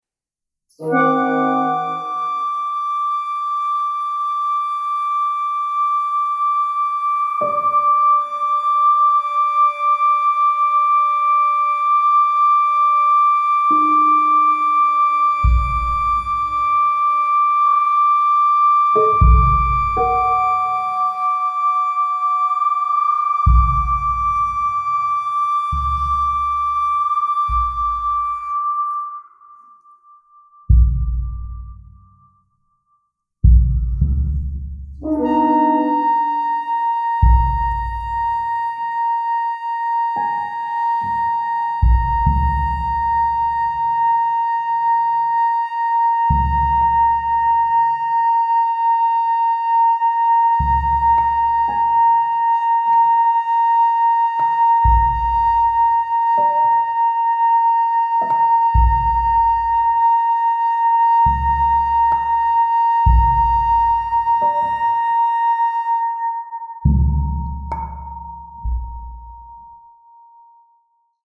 flute
clarinet
voice, whistle & sine tone
French horn
prepared piano
cello
bassoon
percussion
Recorded Feb 22, 2023, WFMT Studio, Chicago, IL